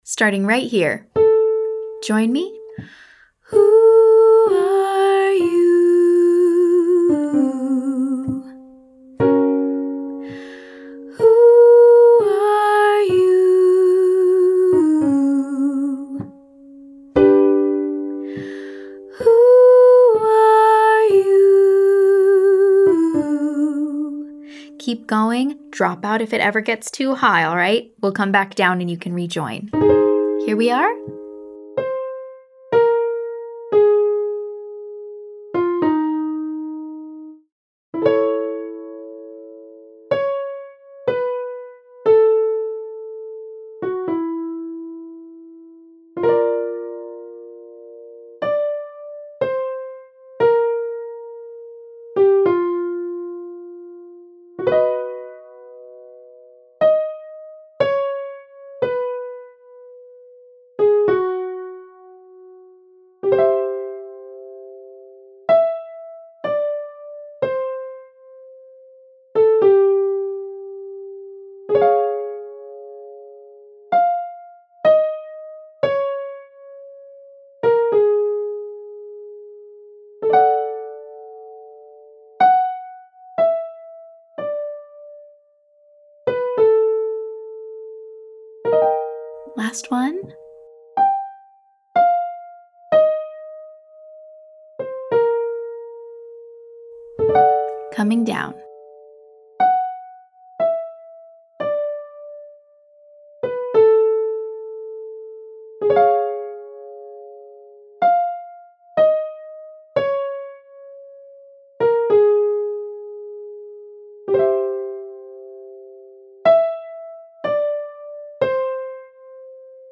Day 10: Light Head Voice (Falsetto)
Just for fun, we’re throwing a riff on the end.
Exercise 2: Who are you / Who am I 3-2-1–65